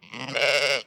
sounds_sheep_baa.ogg